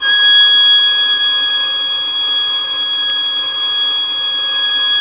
Index of /90_sSampleCDs/Roland - String Master Series/STR_Violin 4 nv/STR_Vln4 _ marc